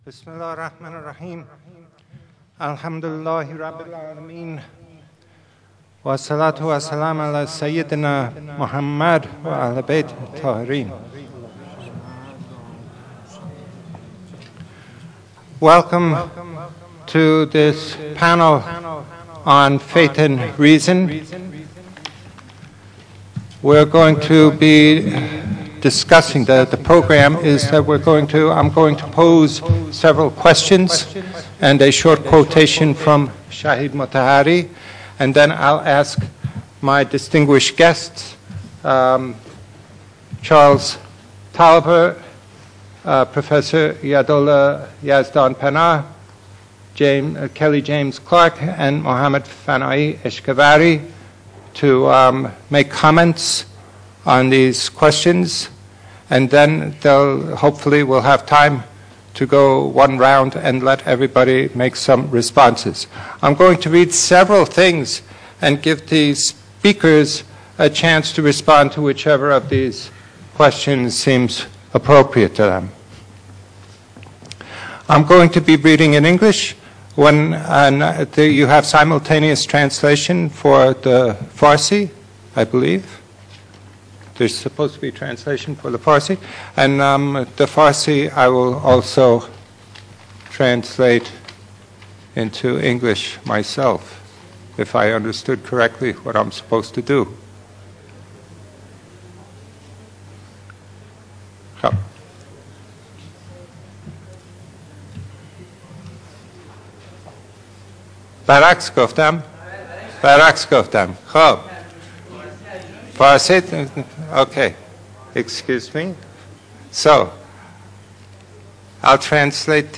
صوت میزگرد «عقل و دین» با حضور اساتید مسلمان و مسیحی
این میزگرد در خردادماه سال ۱۳۹۳ در جریان همایش بین‌المللی اندیشه‌های فلسفی استاد مطهری برگزار شد.